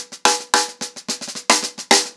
TheQuest-110BPM.17.wav